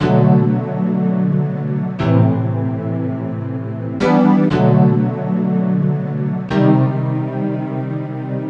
classical_0008.mp3